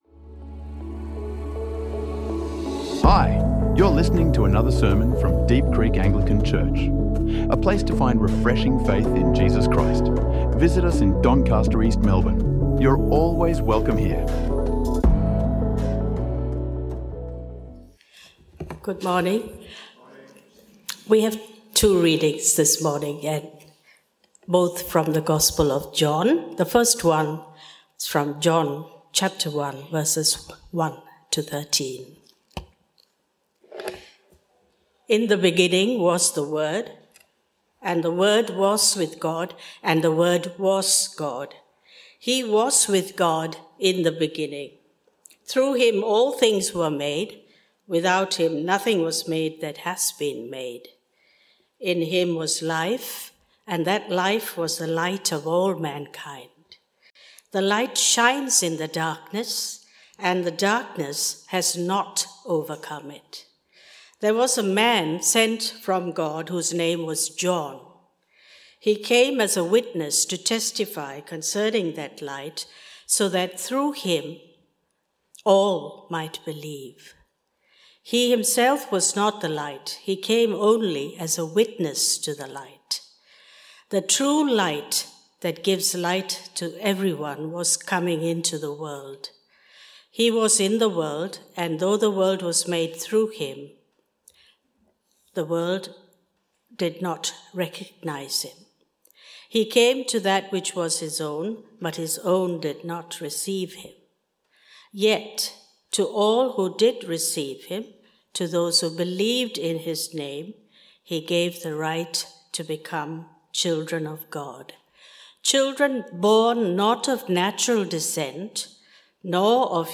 Children of Glory | Sermons | Deep Creek Anglican Church